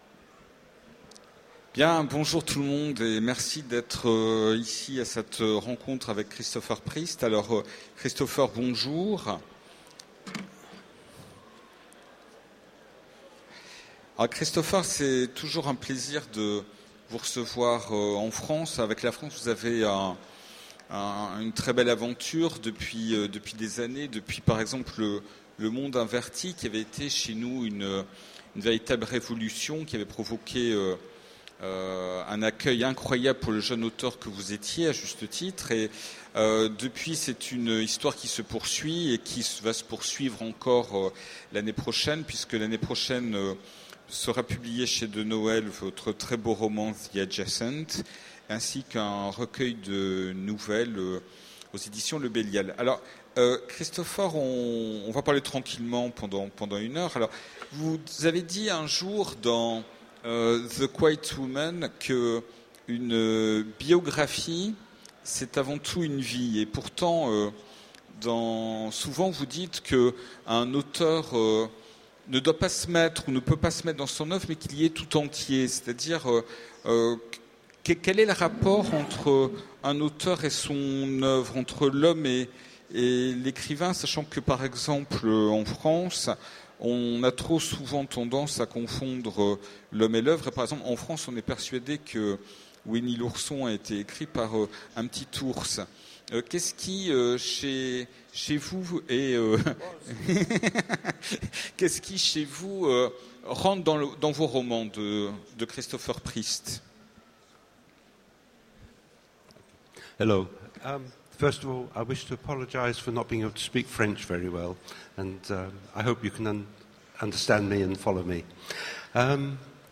Utopiales 2014 : Rencontre avec Christopher Priest
- le 31/10/2017 Partager Commenter Utopiales 2014 : Rencontre avec Christopher Priest Télécharger le MP3 à lire aussi Christopher Priest Genres / Mots-clés Rencontre avec un auteur Conférence Partager cet article